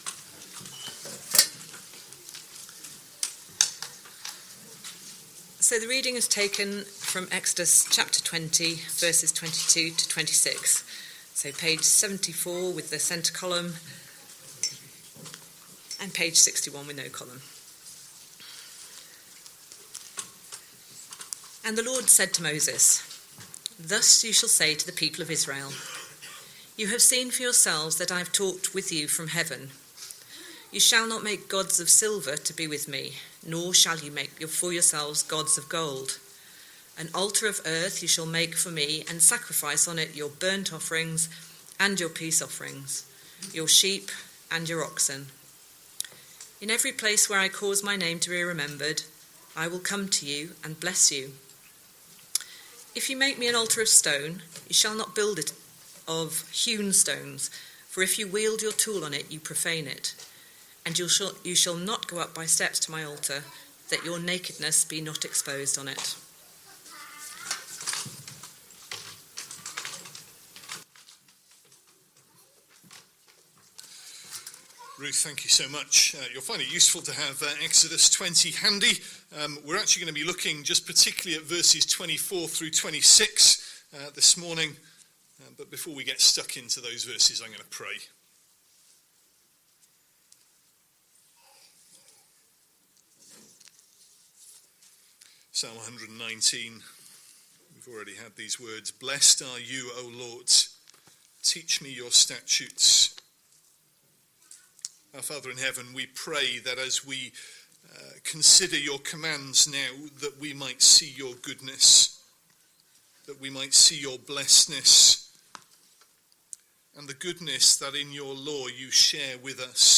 Sunday Evening Service Speaker